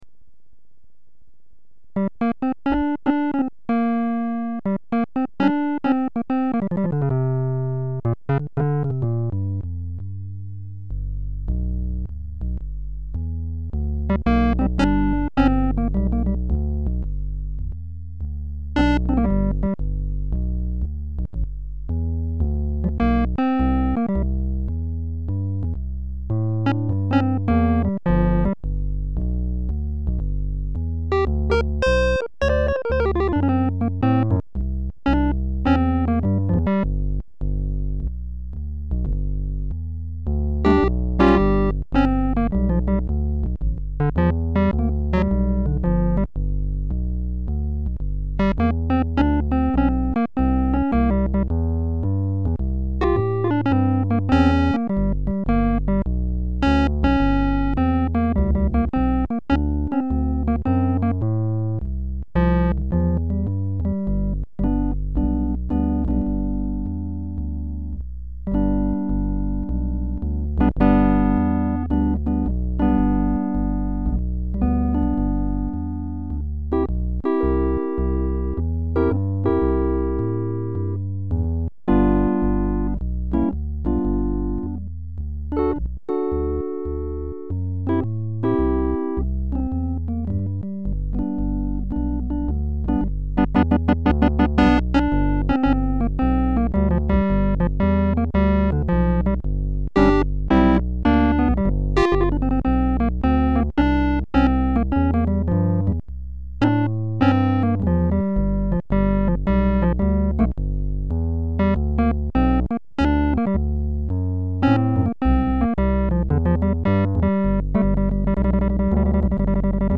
lab Yamaha DX 7
vn-Rhodes Blues.mp3